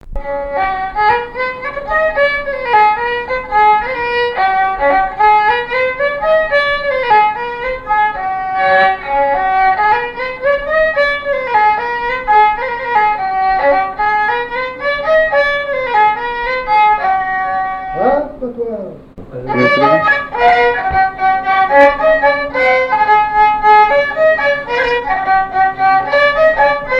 Chants brefs - A danser
polka des bébés ou badoise
répertoire au violon et à la mandoline
Pièce musicale inédite